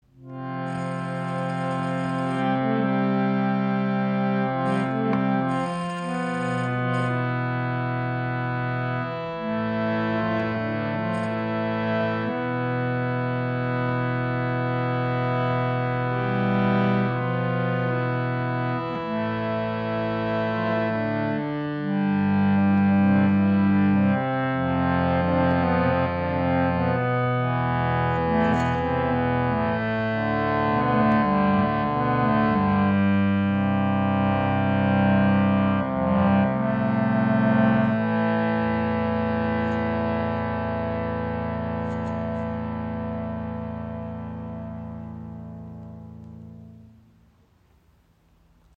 Das Bhava Lite ist ein minimalistisches Reiseharmonium mit 32 Tasten.
Stimmung: Concert Pitch / 440 Hz
Klare Klangfarbe: Resonanz durch eine massive Teakholzschale.